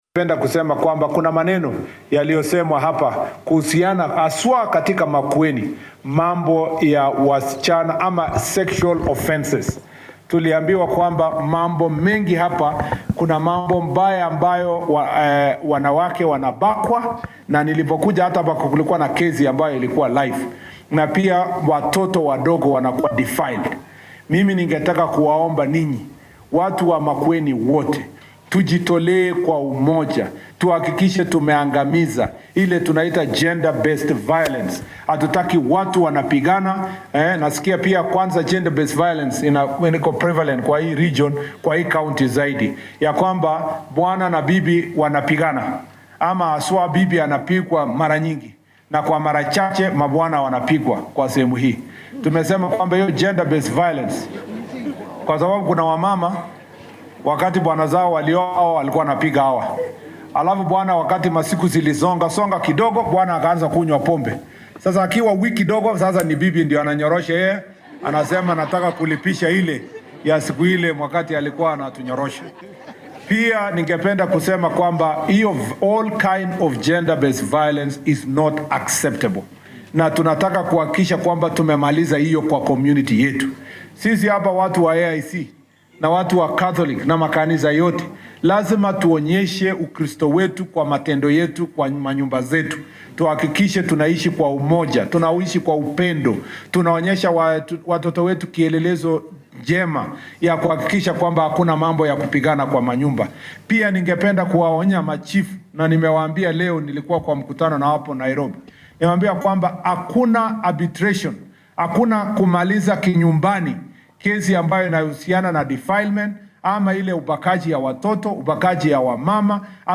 Wasiirka Wasaaradda Arrimaha Gudaha iyo Maamulka Qaran Onesmus Kipchumba Murkomen ayaa soo saaray digniin adag oo ku socota dadka geesta xadgudubyada ku saleysan jinsiga. Murkomen ayaa hadalka ka jeediyay xilli uu booqasho shaqo ku tagay ismaamulka Makueni isagoo sheegay in cid walbo oo lagu helo in ay ku howlantahay jebinta sharciga tallabo laga qaadi doono. Wasiirka ayaa sidoo kale u digey dadka qariyo arrimaha la xiriira kufsiga ay waalidiinta qaarkood u geestaan carruurtooda isaga oo sheegay in arrintaas aan lagu xalin karin hab dhaqameed.